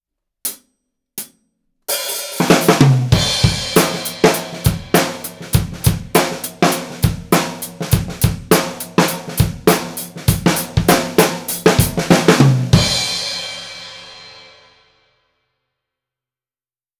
すべて、EQはしていません。
今度は、①に近いサウンドですが、低音が減りシンバル類が
近くに聞こえる様になりましたね！